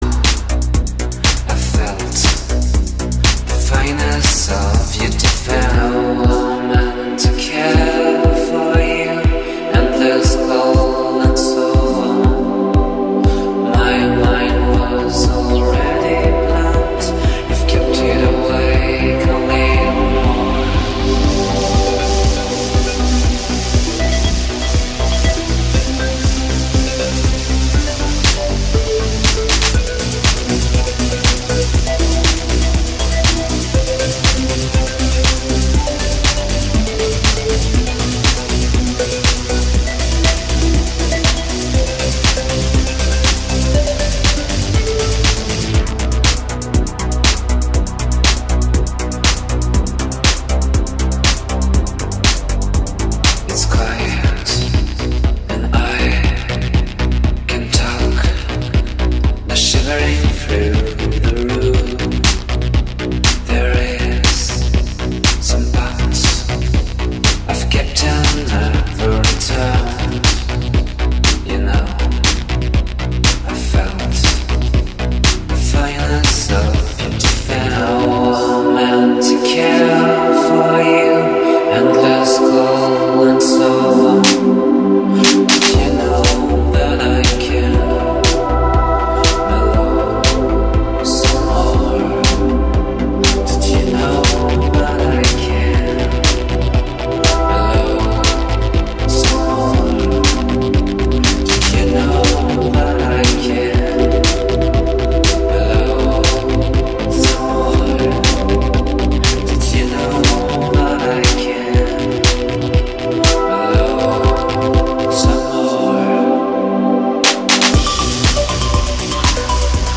Σημείωση: Λόγω τεχνικών προβλημάτων, η εκπομπή ηχογραφήθηκε μισή!